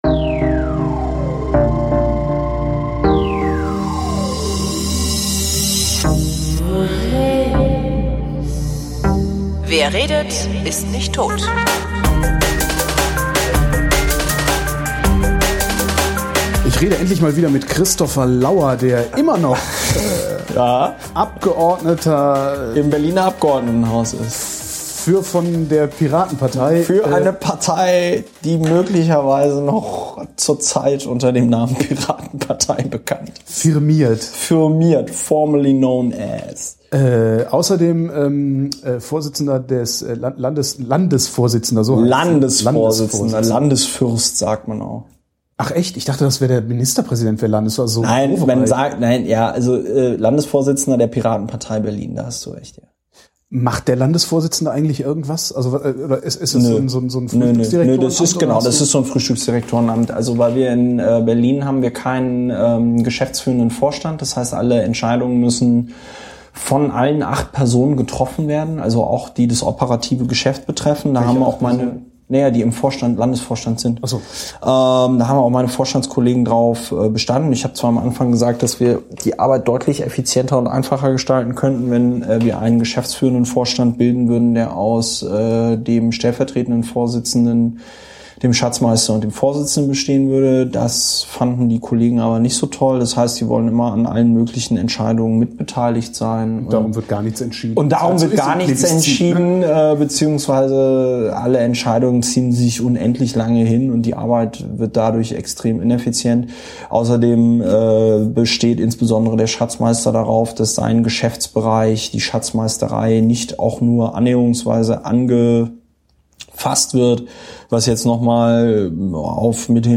Endlich mal wieder bei Christopher Lauer aufgeschlagen, um unsere Reihe weiterzuführen. Diesmal in der Küche und freudiger Erwartung gar köstlichen Bieres.